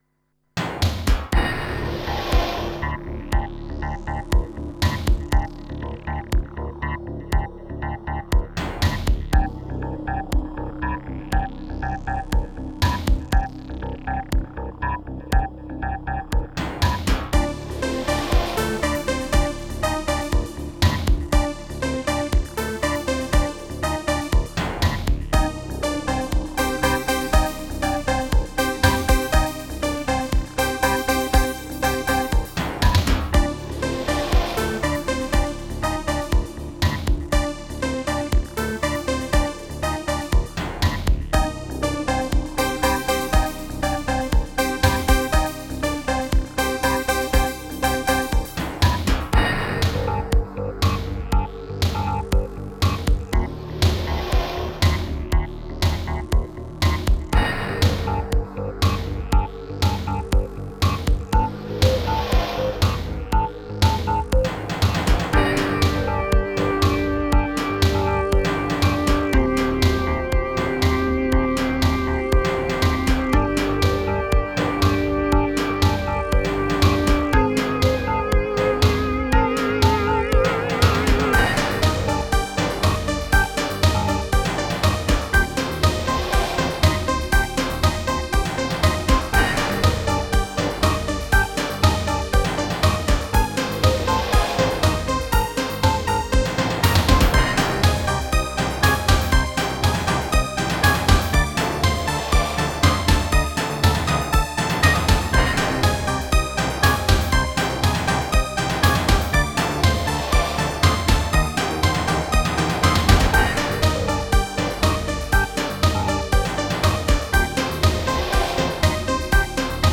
Single Mix